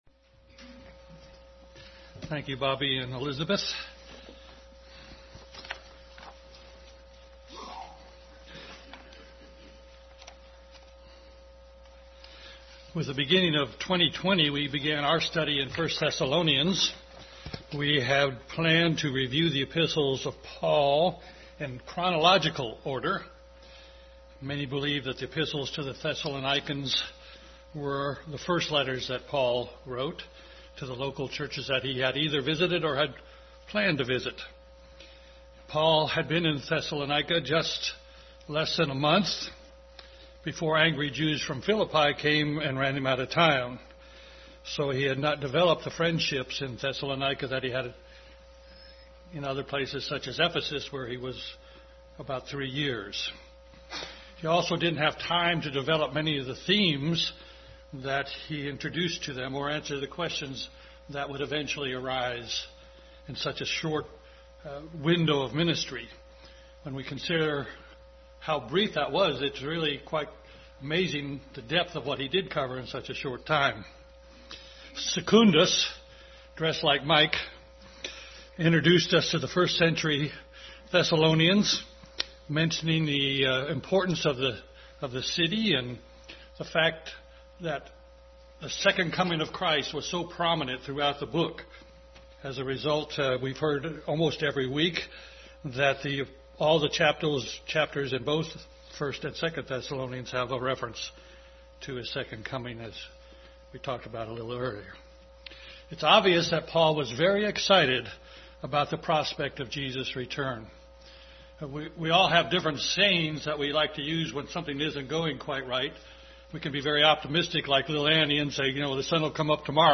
Bible Text: 1 Thessalonians 4:13-5:11 | Adult Sunday School continued study of the epistles of Paul,